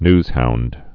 (nzhound, nyz-)